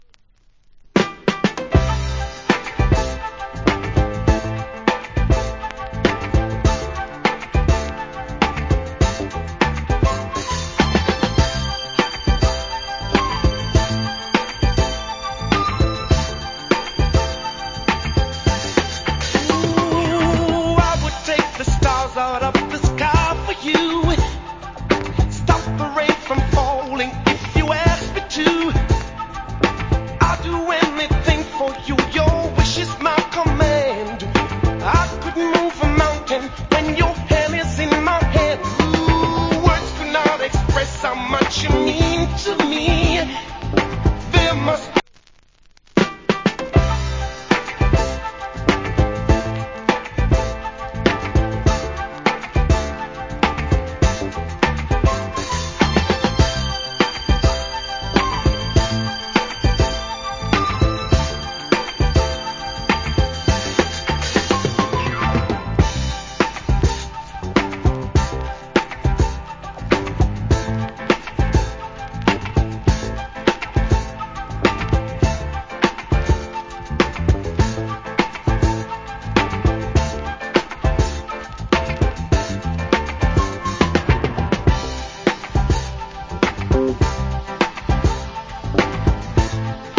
Cool Soulful Vocal.